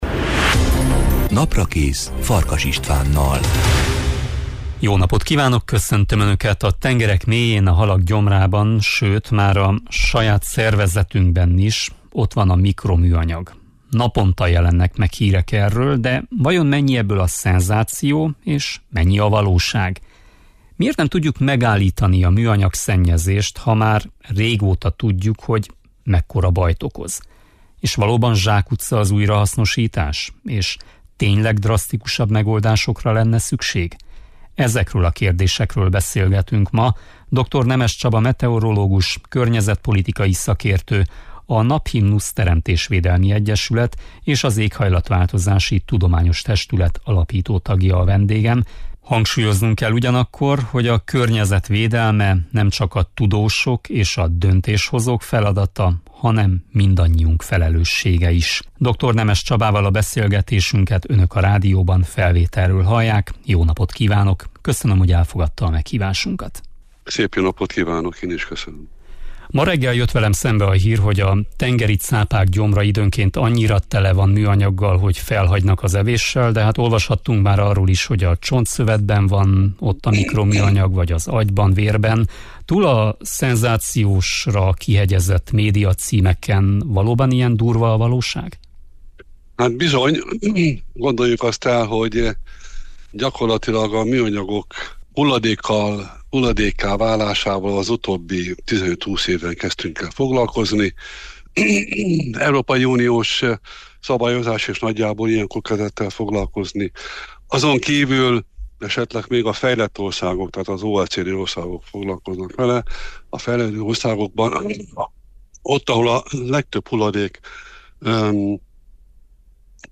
Ezekről a kérdésekről beszélgetünk